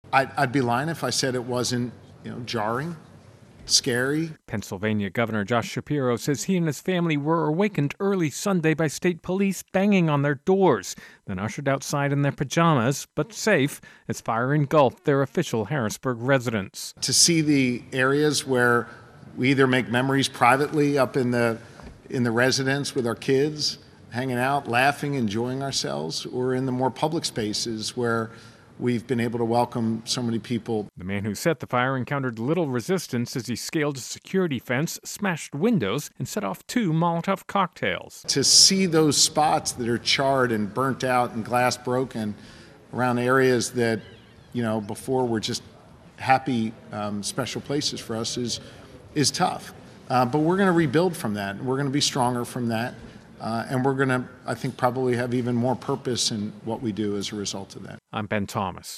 In his words: Shapiro recounts evacuating arson fire in pajamas at Pennsylvania governor's residence